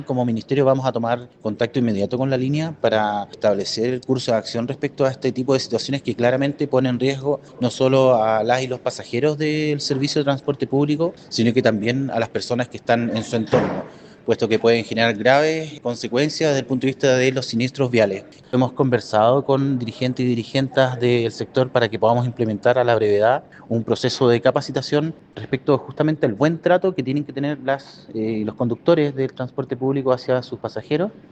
Consultado por Radio Bío Bío, el seremi de Transporte, Pablo Joost, confirmó una investigación del caso.